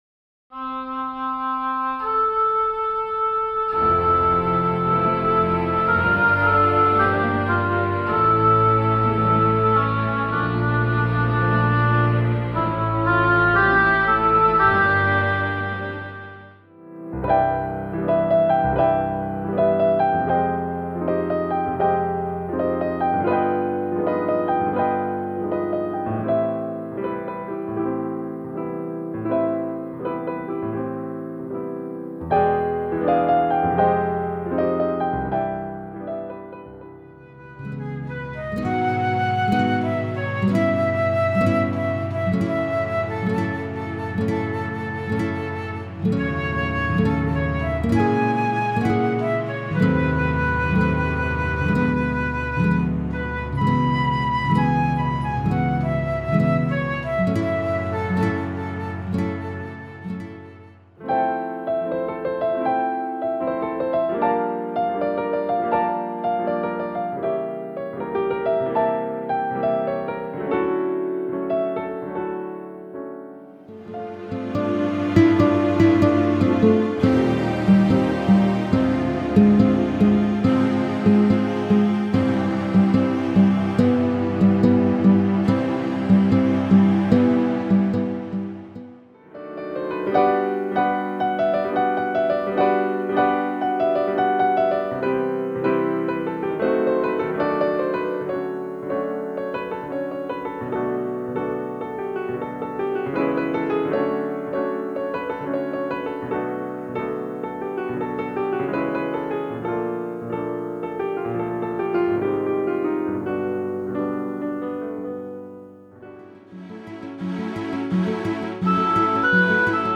🎧 High-quality sound, designed for premium spaces.